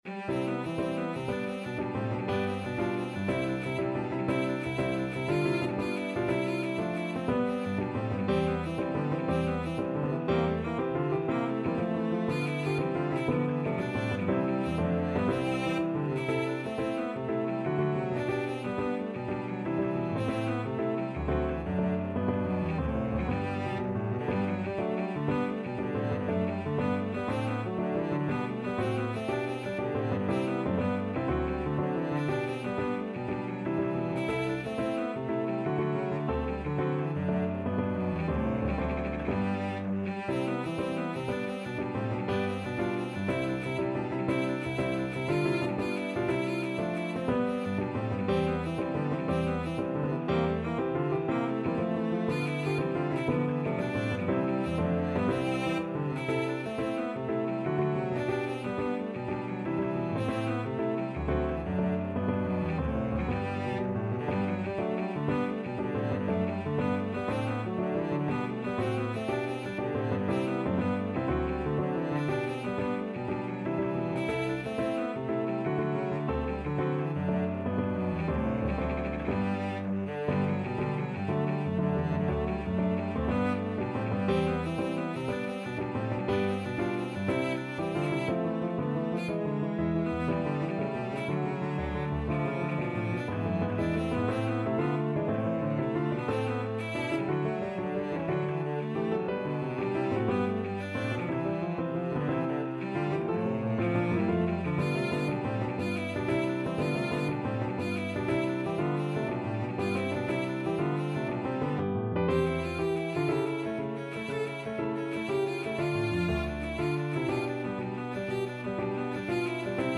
Cello
C major (Sounding Pitch) (View more C major Music for Cello )
G3-A5
IV: Allegro (View more music marked Allegro)
4/4 (View more 4/4 Music)
Classical (View more Classical Cello Music)